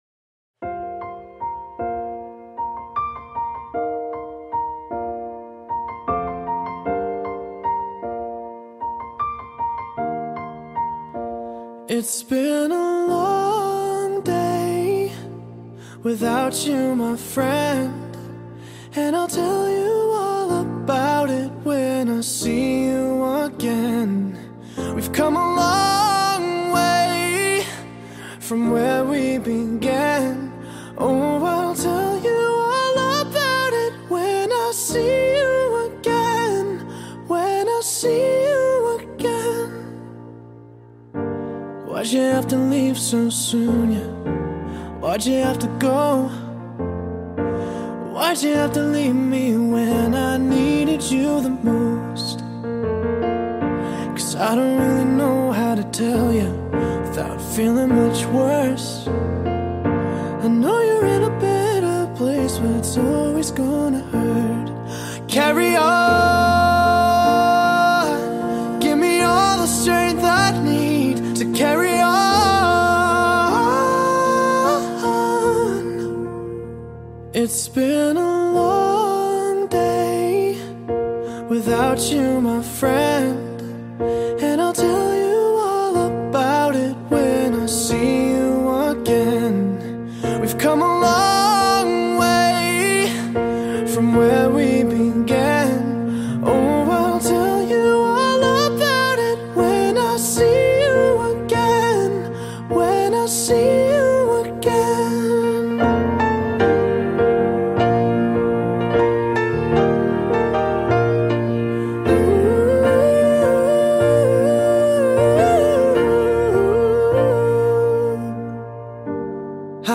I just love this piano version ❤‍ (I do not own anything!)